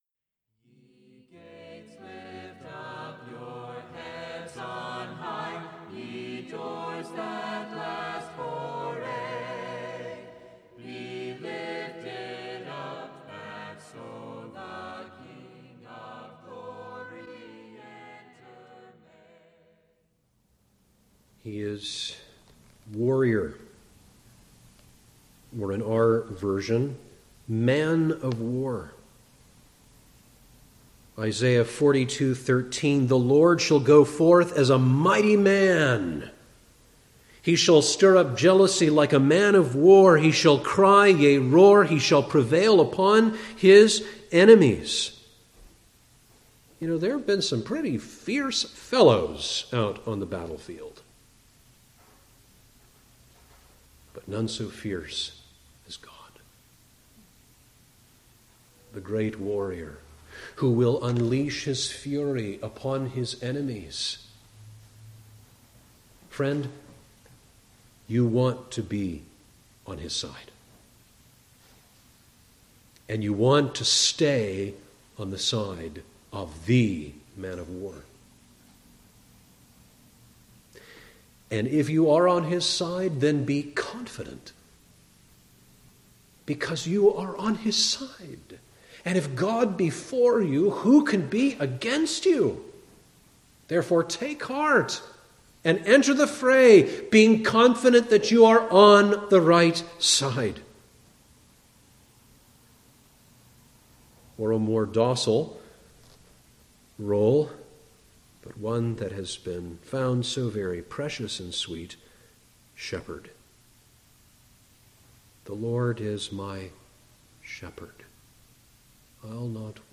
Listen to this short, 3 minute audio clip from a recent sermon on God as Warrior and Shepherd.